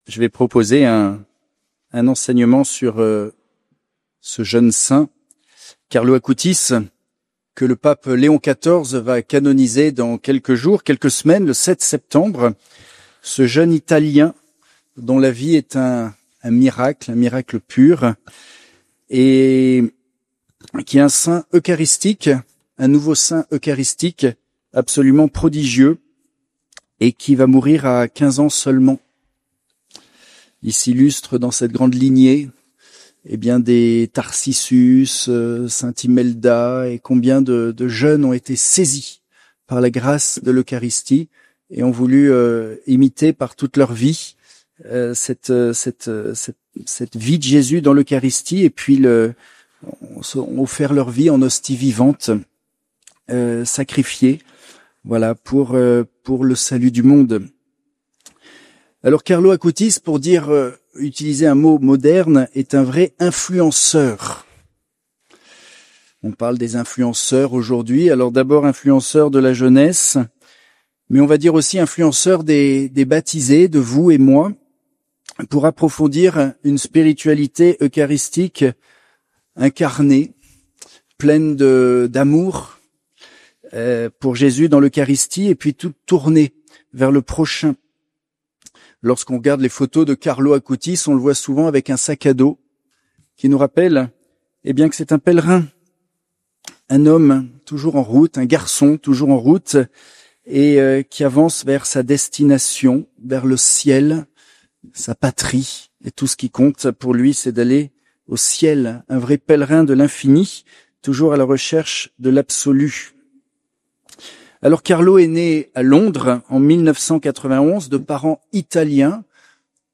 Nd Laus, Congrès Adoratio